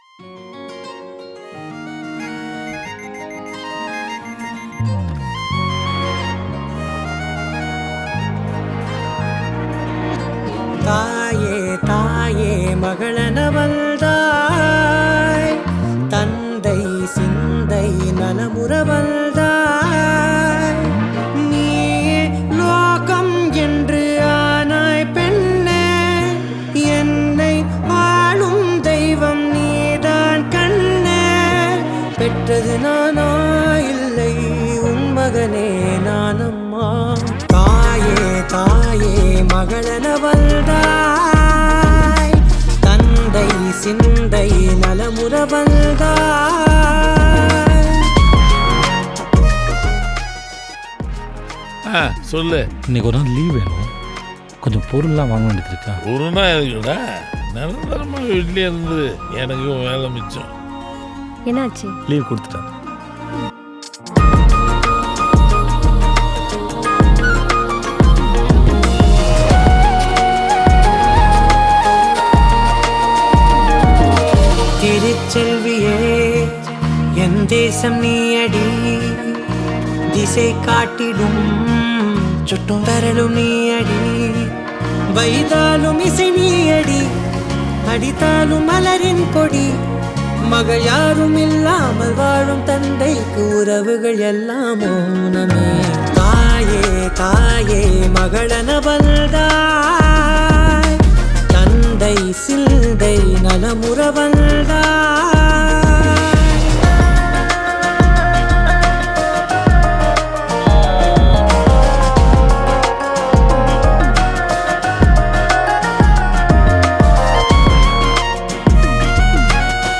Key board
Guitar
Sound Town Studio, Chennai
Flute
RM Studio Chennai
Rhythms